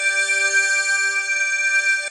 orb_glow_03.ogg